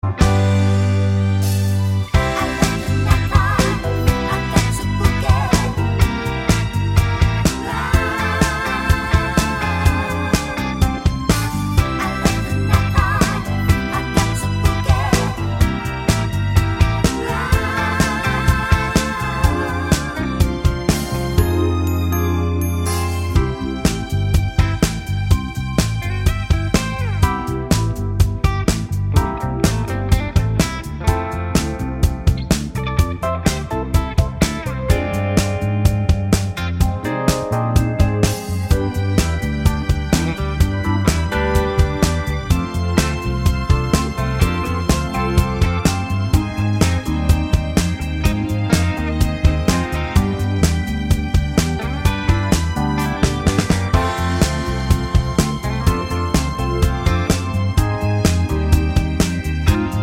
no Backing Vocals Disco 3:16 Buy £1.50